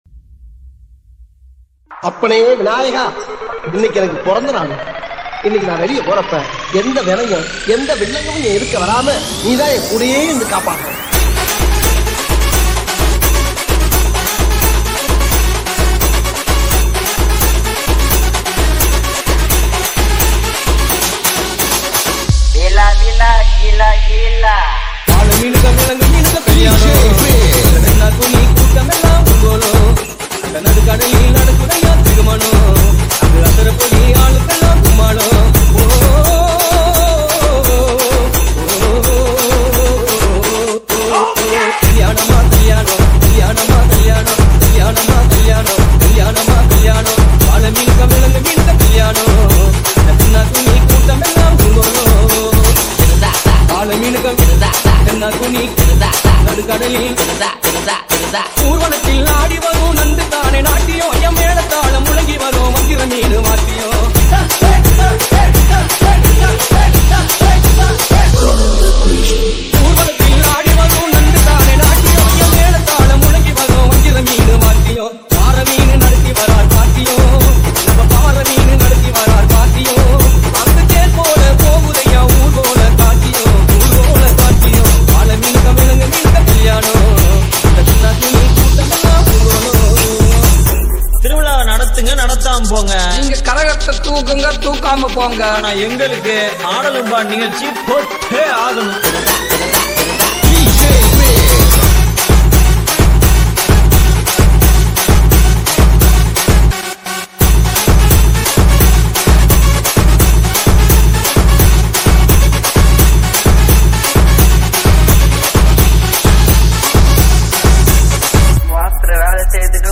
ALL TAMIL ROMANTIC DJ REMIX